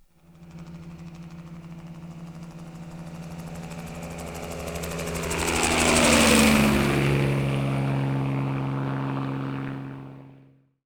HELICOPP.WAV